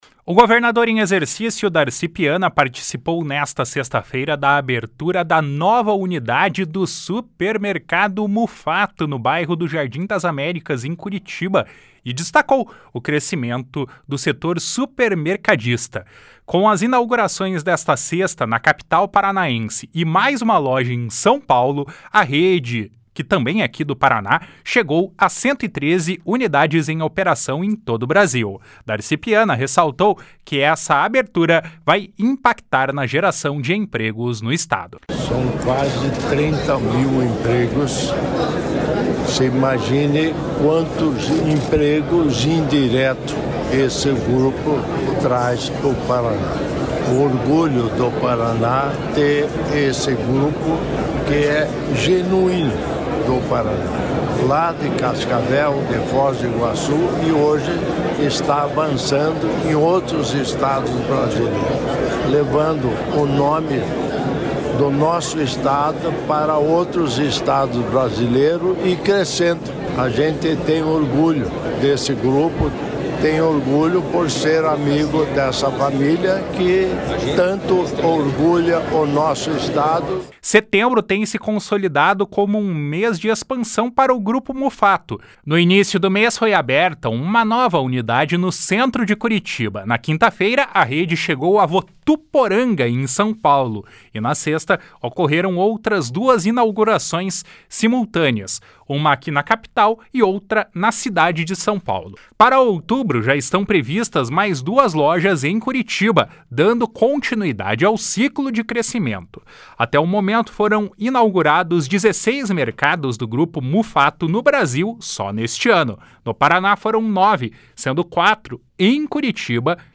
// SONORA DARCI PIANA //